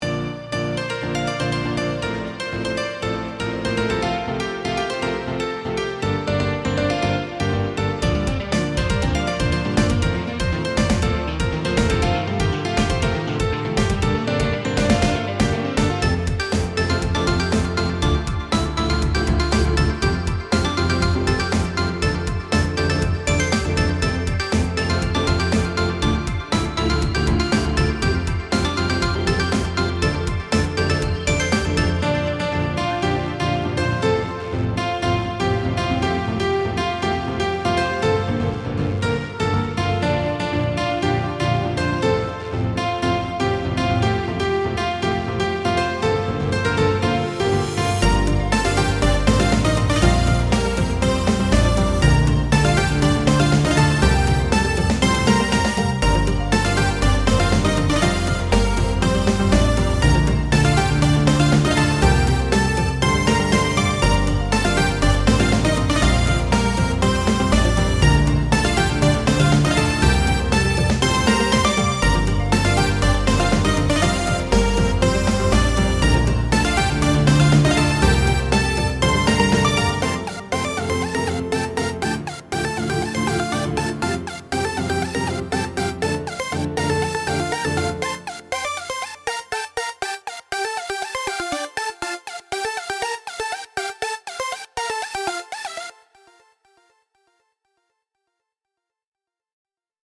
The melody is complicated, but I hope it can be used in a scene in the game.